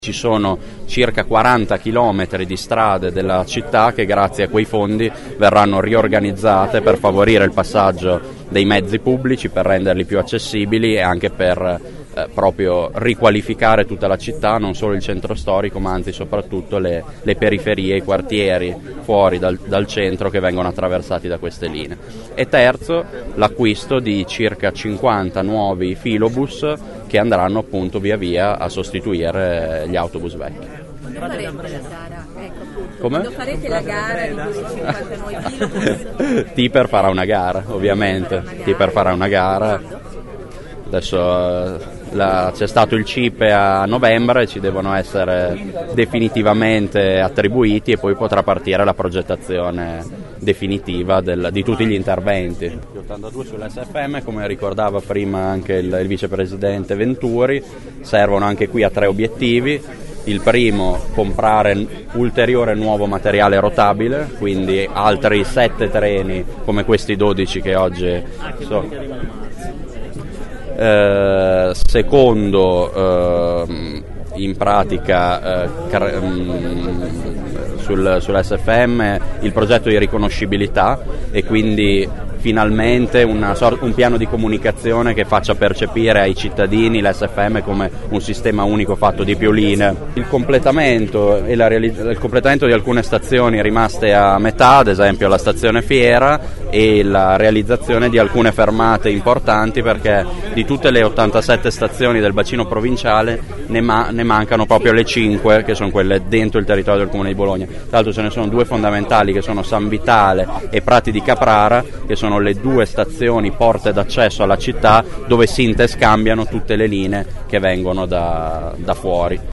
Ascolta l’assessore Colombo